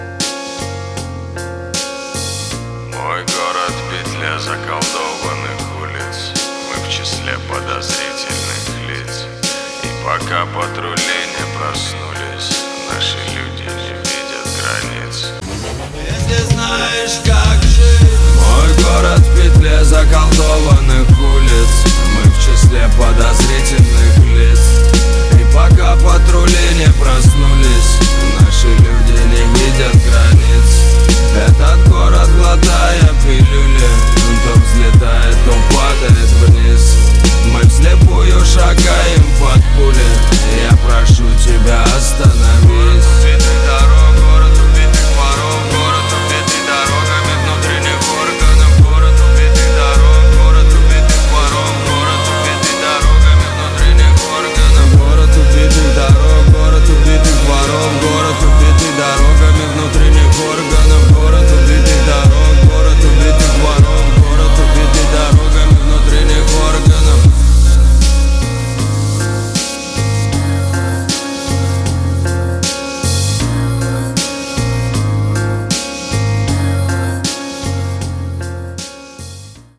• Качество: 320, Stereo
русский рэп
спокойные